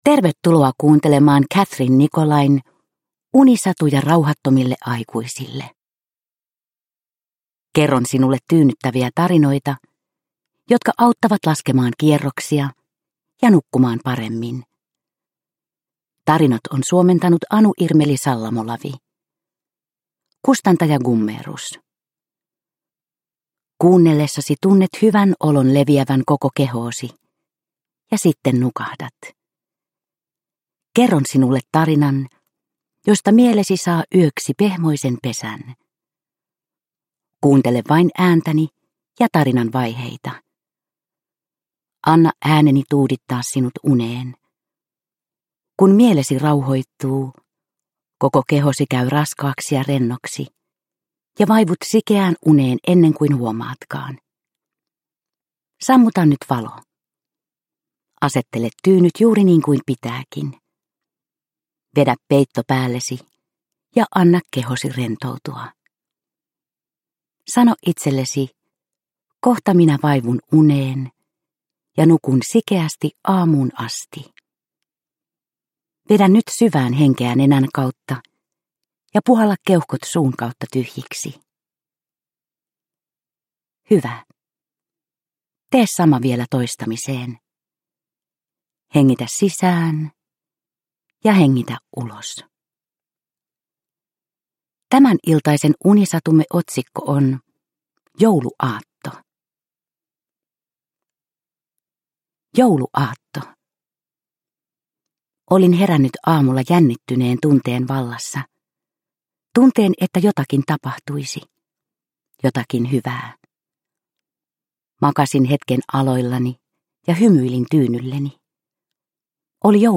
Unisatuja rauhattomille aikuisille 13 - Jouluaatto – Ljudbok – Laddas ner